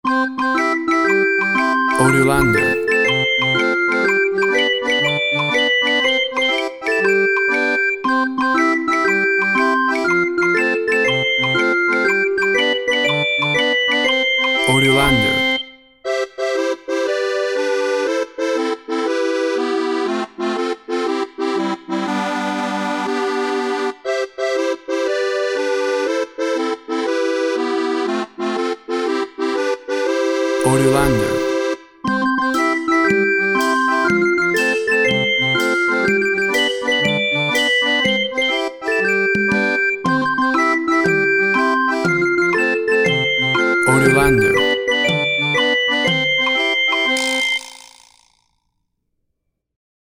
Tempo (BPM) 120